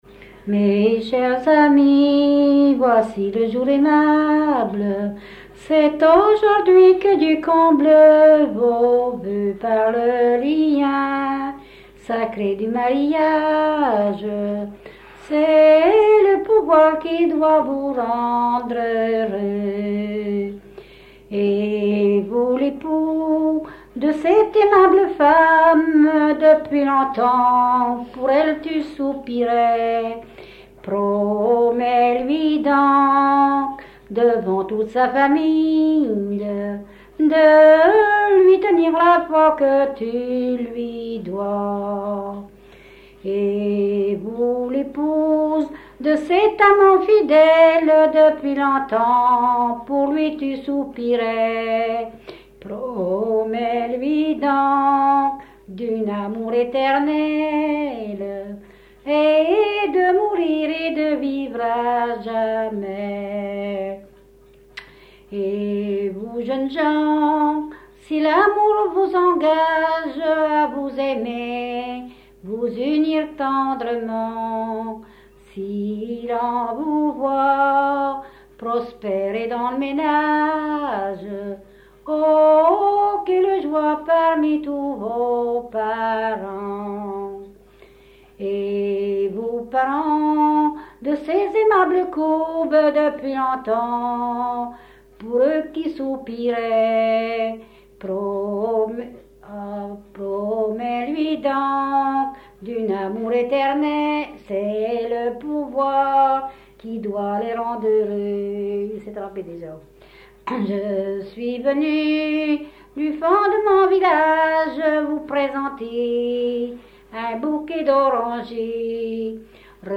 Localisation Barbâtre (Plus d'informations sur Wikipedia)
Usage d'après l'analyste circonstance : fiançaille, noce ;
Genre strophique
Catégorie Pièce musicale inédite